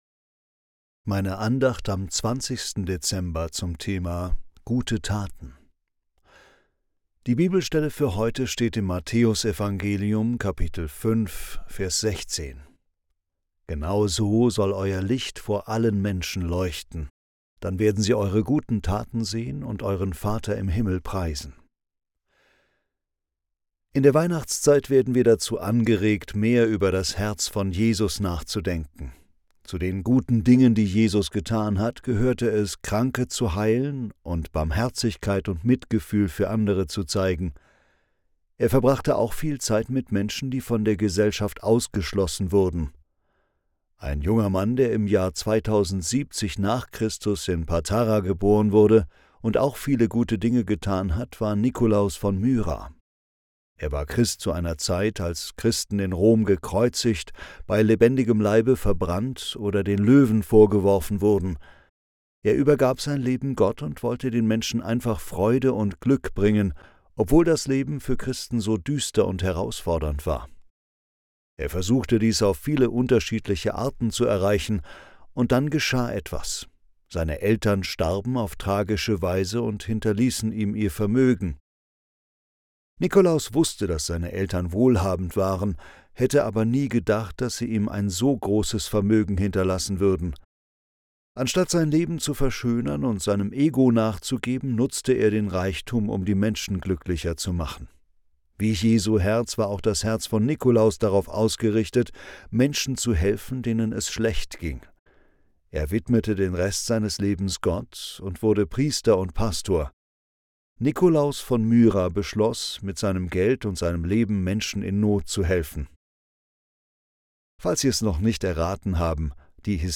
Andacht zum 20. Dezember
Andacht_2012(1).mp3